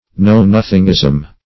Search Result for " know-nothingism" : The Collaborative International Dictionary of English v.0.48: Know-nothingism \Know"-noth`ing*ism\, n. The doctrines, principles, or practices, of the Know-nothings.
know-nothingism.mp3